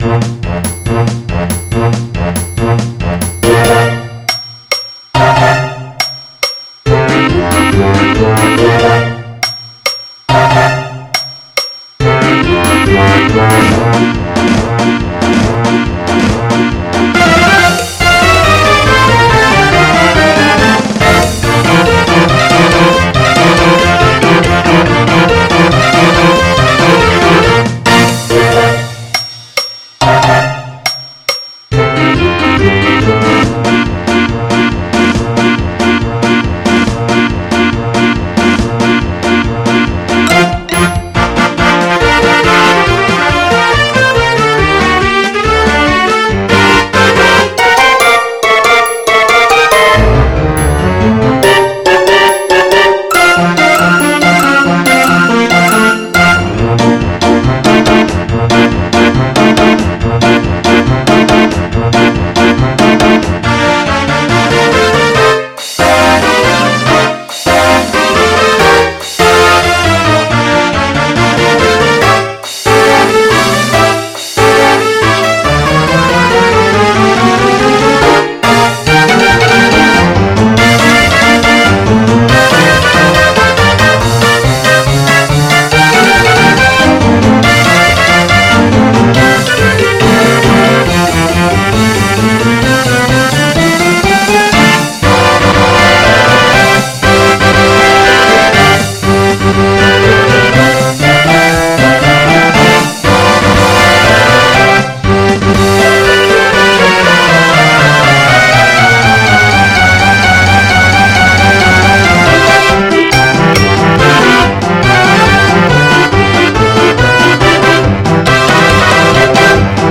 MIDI 96 KB MP3 (Converted) 2.67 MB MIDI-XML Sheet Music